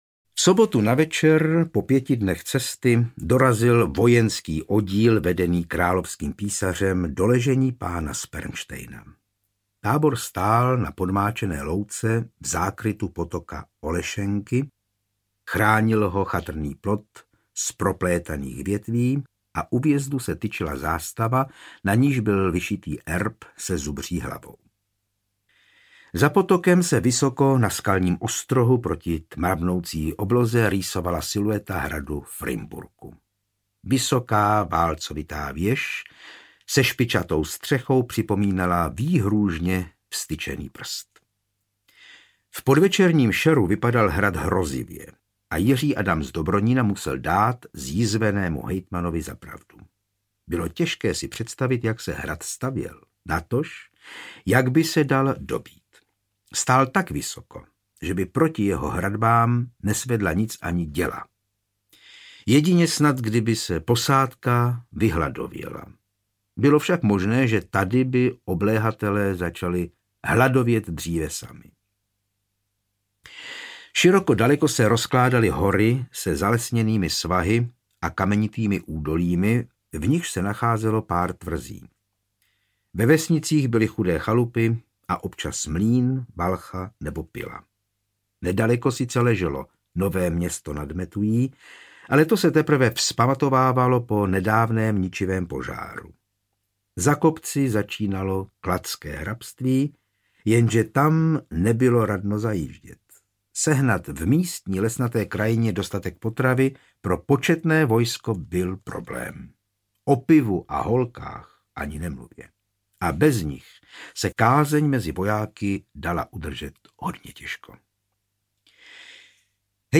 Jiří Adam z Dobronína – Zločiny z vášně audiokniha
Ukázka z knihy
Audiokniha je bez hudebních předělů a podkresů.